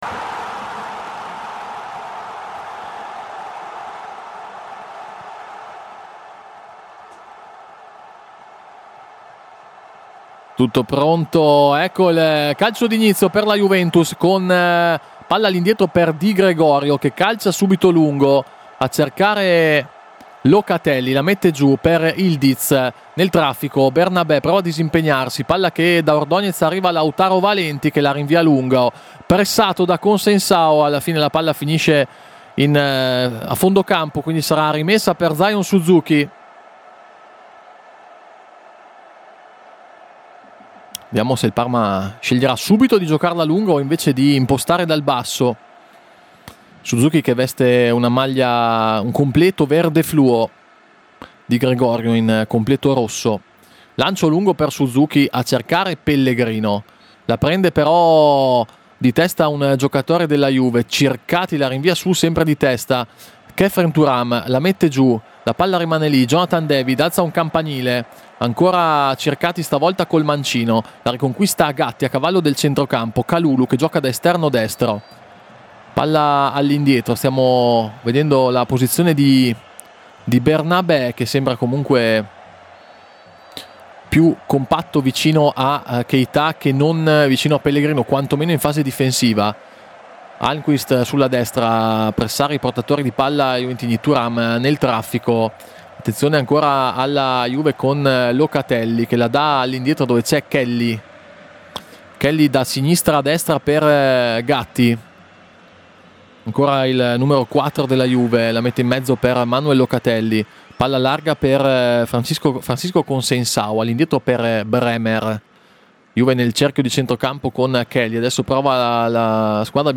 Radiocronache Parma Calcio Juventus - Parma 1° tempo - 24 agosto 2025 Aug 24 2025 | 00:47:34 1x 00:00 / 00:47:34 Subscribe Share RSS Feed Share Link Embed